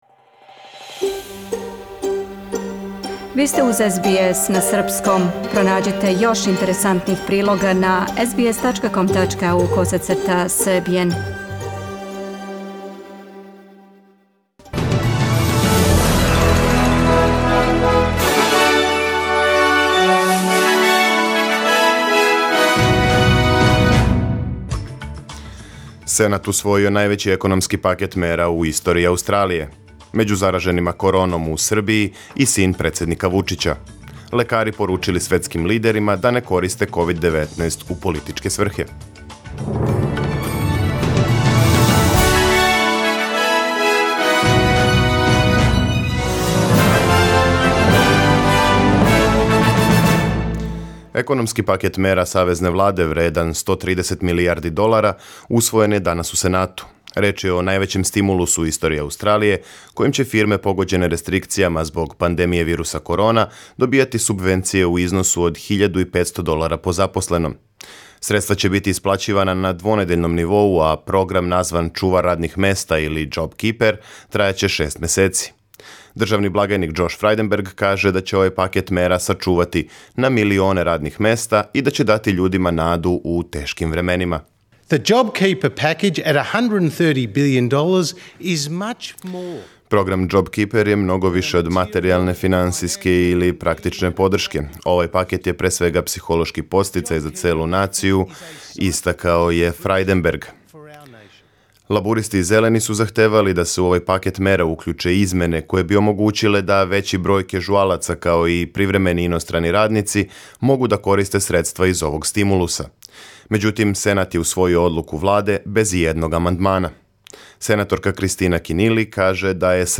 Преглед вести за 9. април 2020.